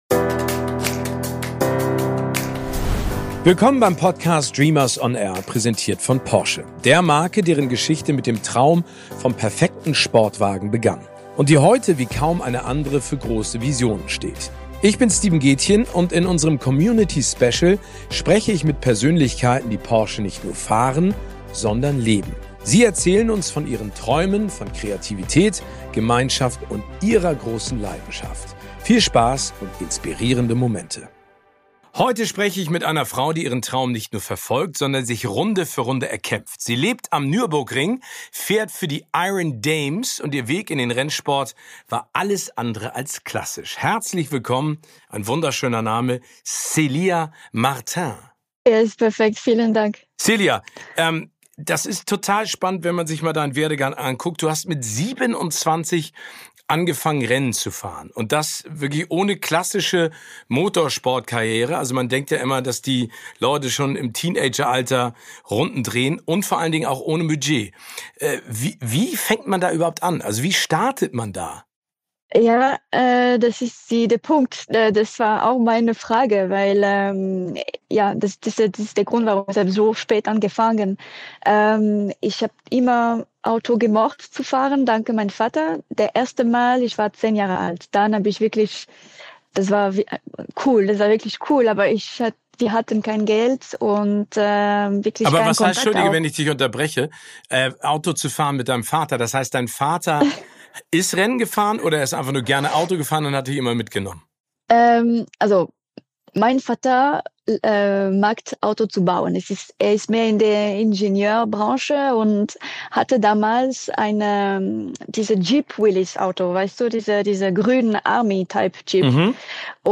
Im Gespräch mit Gastgeber Steven Gätjen erzählt sie uns von ihrem faszinierenden Weg bis zum Fahrersitz im Team der Iron Dames, den Widerständen, die sie dabei überwinden musste und wie die Nordschleife zu ihrer 2. Heimat wurde.